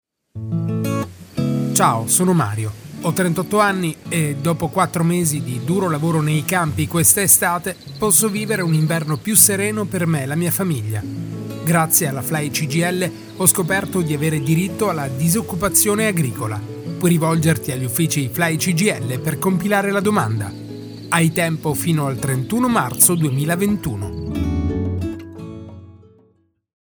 Spot RADIO DS agricola 2021